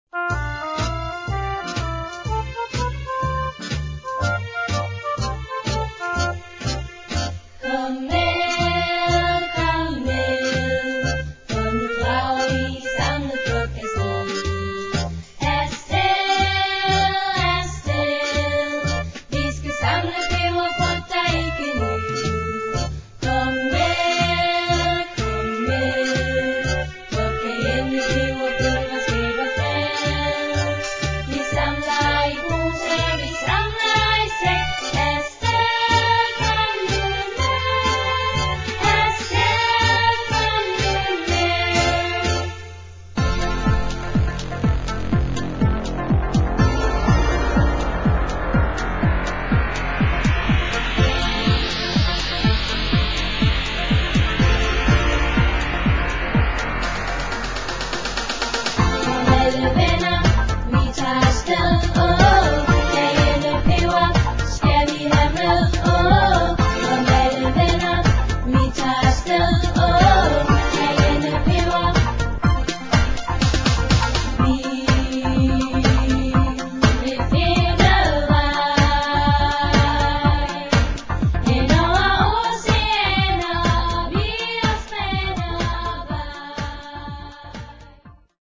Lutter sangbare og ørehængende slagere finder man her!